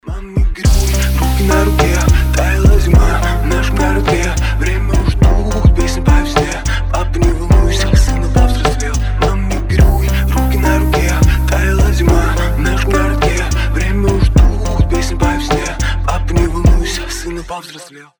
атмосферные
спокойные
медленные